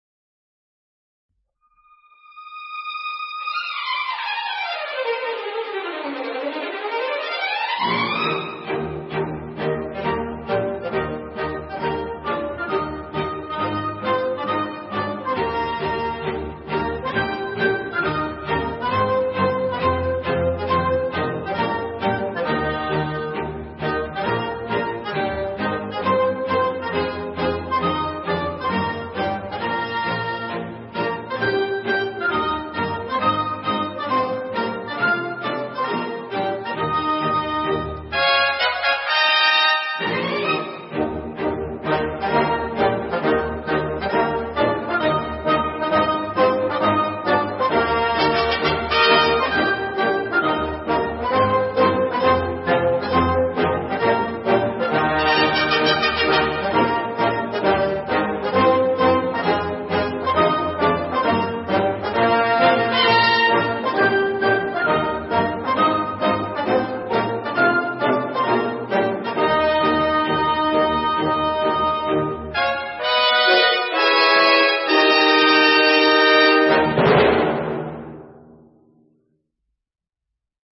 File nhạc không lời